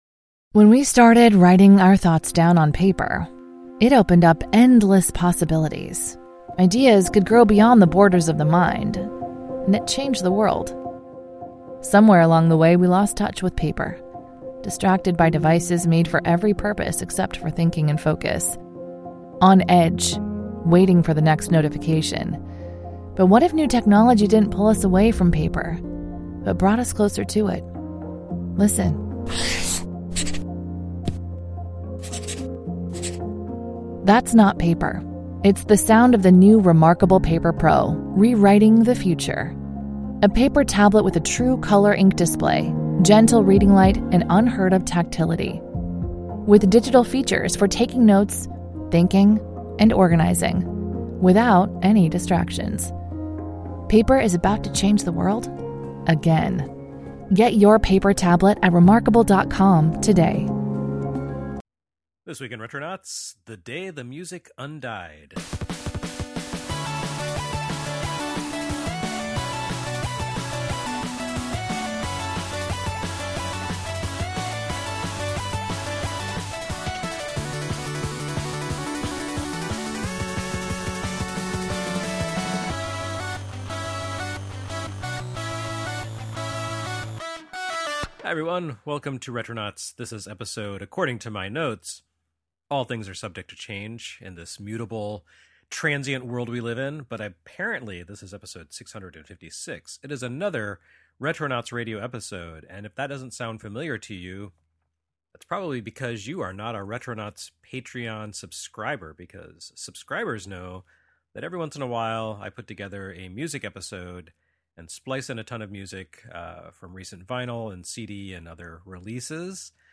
featuring tunes aplenty!